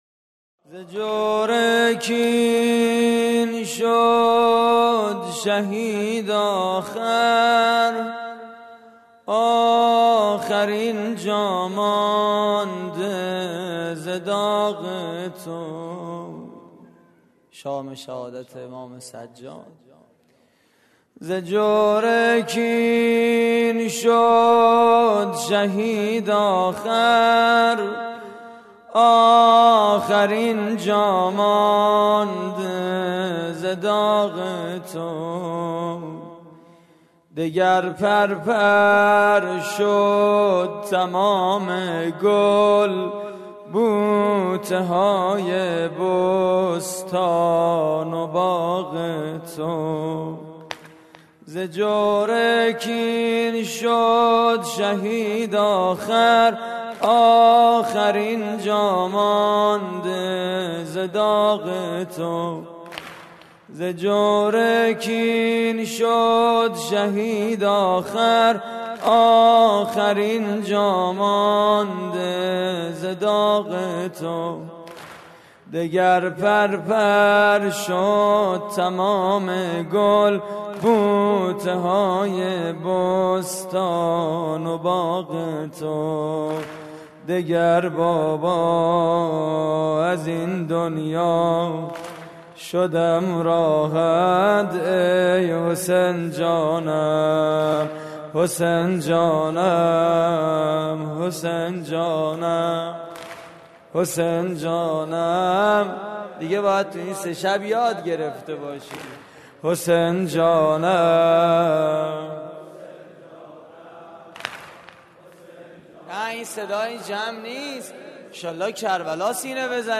واحد: آخرین جامانده ز داغ تو
مراسم عزاداری شهادت امام سجاد (ع)
دانشگاه صنعتی شریف